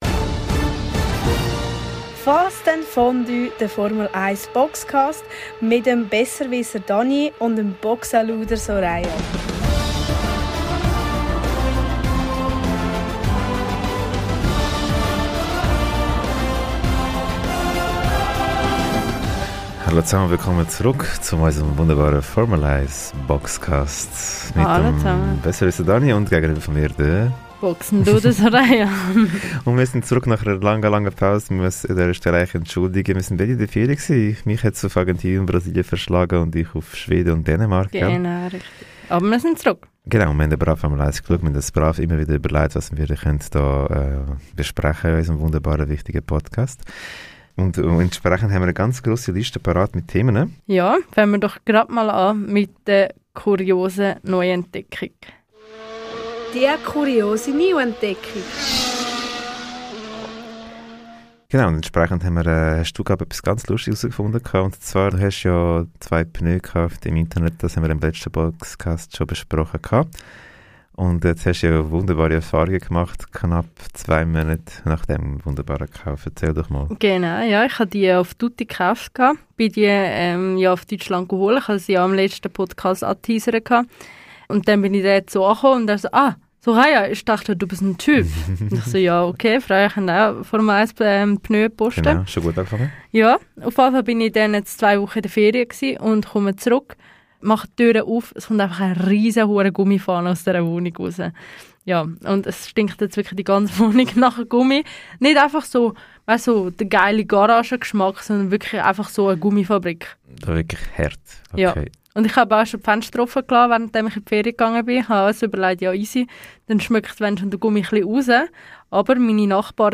Diese handelt unter anderem von den Schweizern in der Formel 1. Wir berichten aus dem Formel-1-Museum von Rennfahrer Jo Vonlanthen in Langenthal. Weiter blicken wir auf das Rennen in Monaco zurück und diskutieren darüber, ob es diesen Grand Prix künftig wirklich braucht.